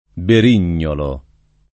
berignolo [ ber & n’n’olo ; non - 0 lo ]